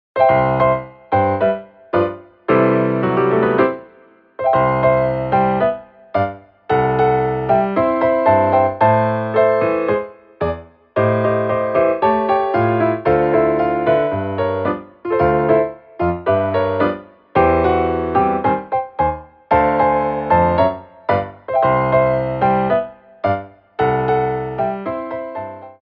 TANGO
8x8 (Slow)